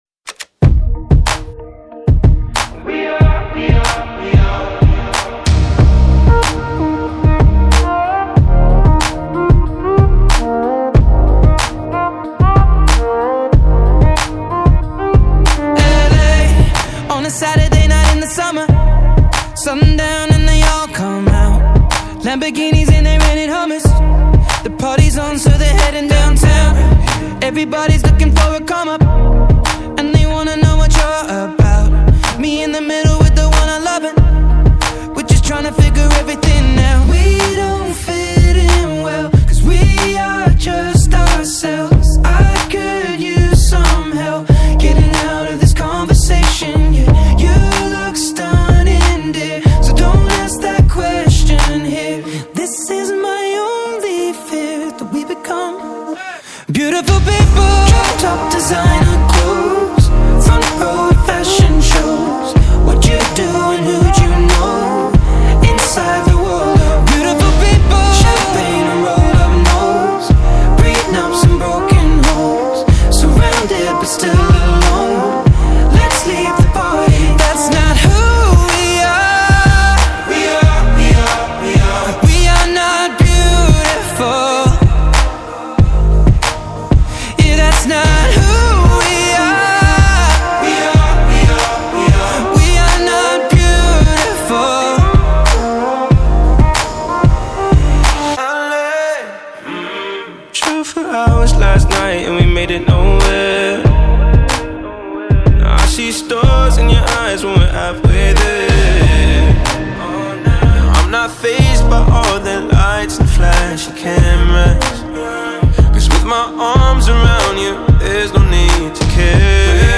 Pop, Hip Hop, R&B